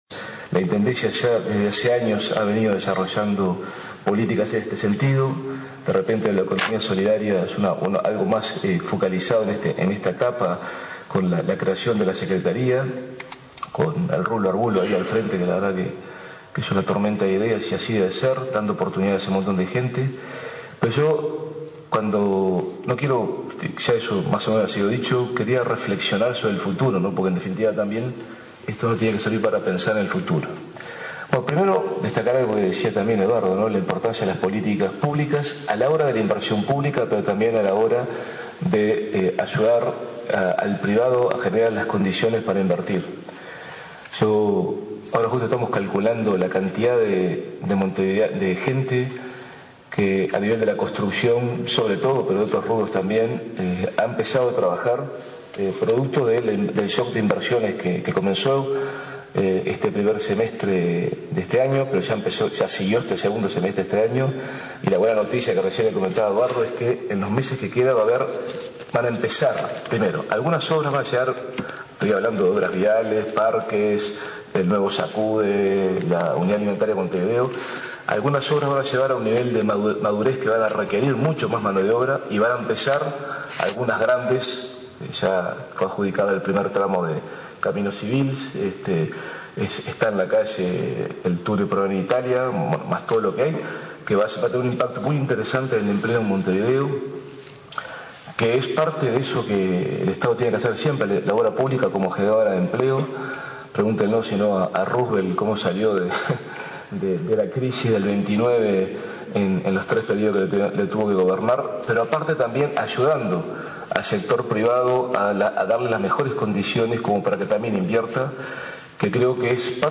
Los ministros de Industria y de Trabajo, Carolina Cosse y Ernesto Murro, y el intendente de Montevideo, Daniel Martínez, disertaron este martes en el seminario “Montevideo, ciudad de oportunidades de empleo y trabajo”, que se realizó en la intendencia capitalina. El intendente Daniel Martínez hizo hincapié en la importancia de las políticas públicas para generar inversión y empleo y detalló algunas de las obras en curso.